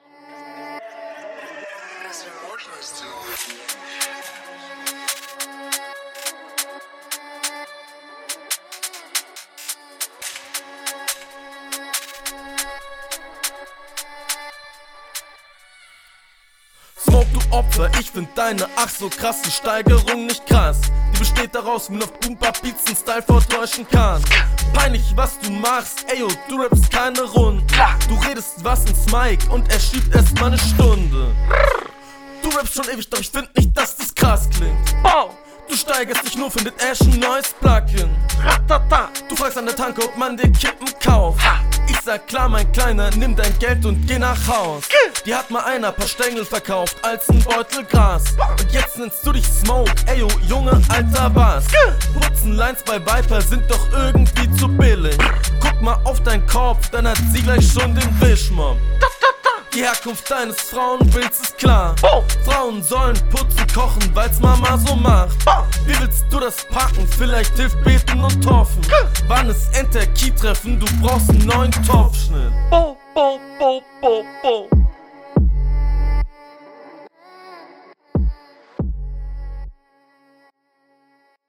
Addlips sind echt zu laut finde ich.